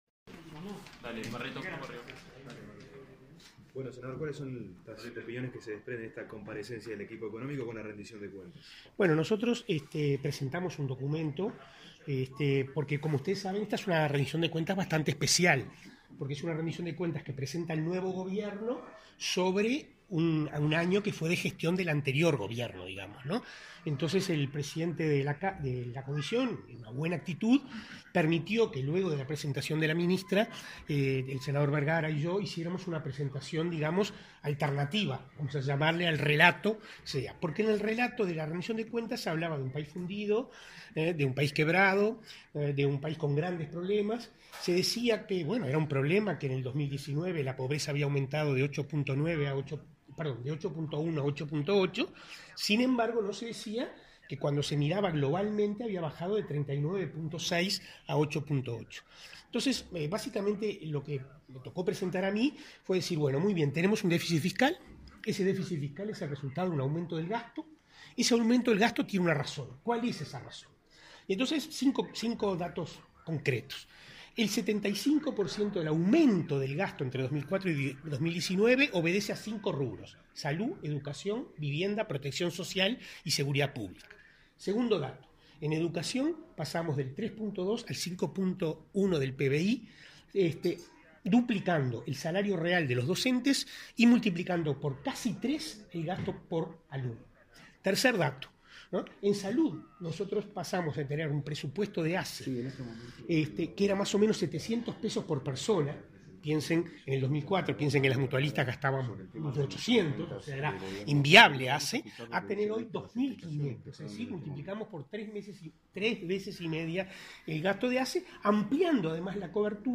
Se adjunta declaraciones a la prensa realizadas por los senadores Daniel Olesker y Mario Bergara